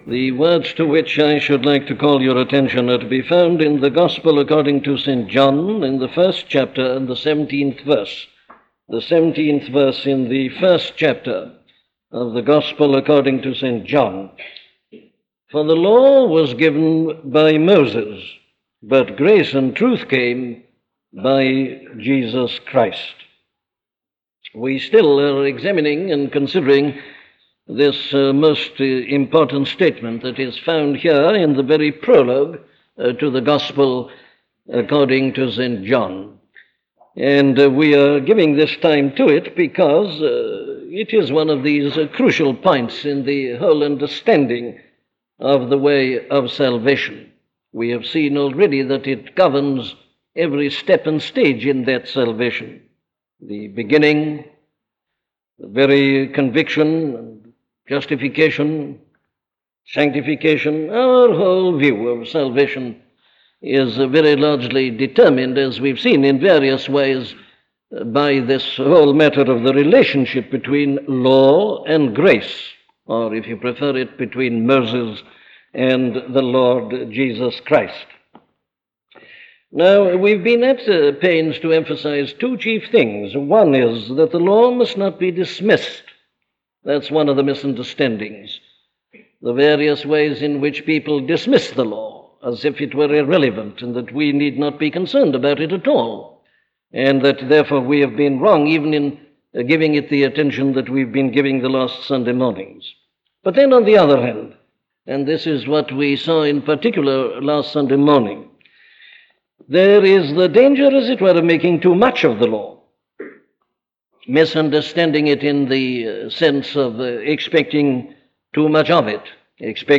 The Law and Grace - a sermon from Dr. Martyn Lloyd Jones